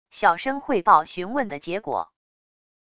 小声汇报询问的结果wav音频